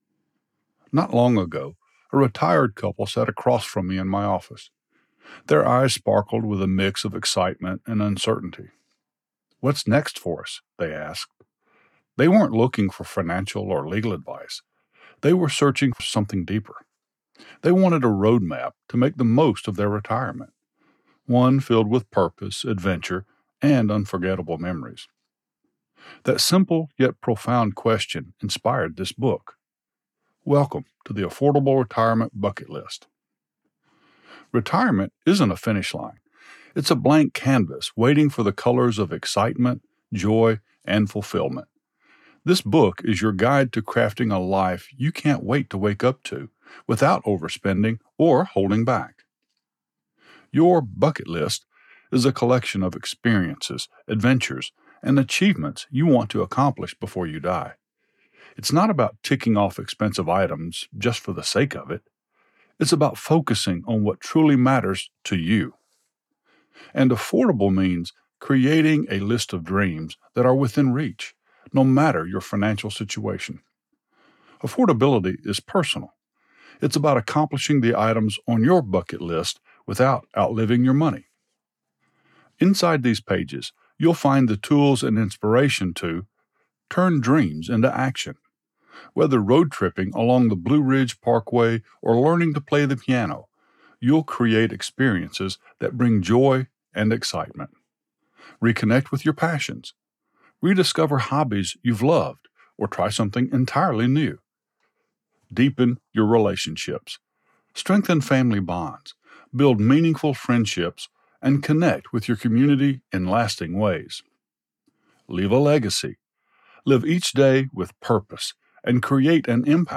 I am a professionally trained voice talent with a mature, slight southern accent.
Audiobook - Non-Fiction
SAMPLE - Audiobook - TARBL - Non-fiction.mp3